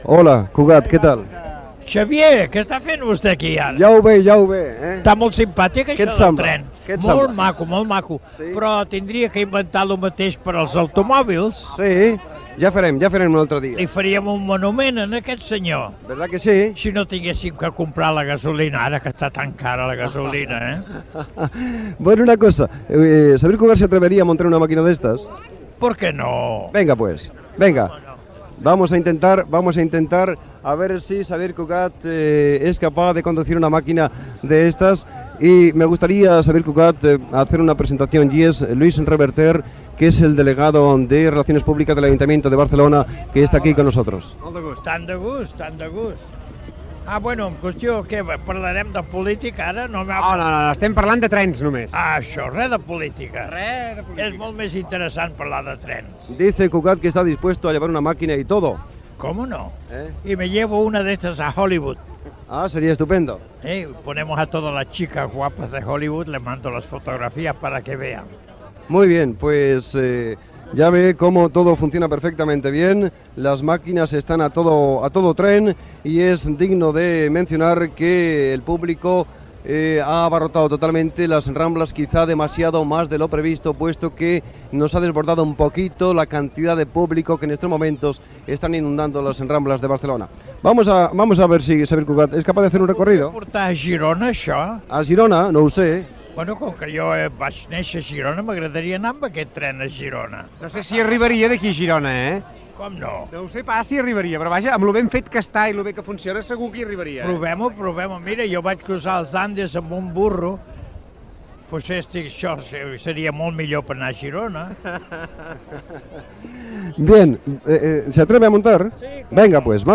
Rudy Ventura toca dos temes amb la seva trompeta
Entreteniment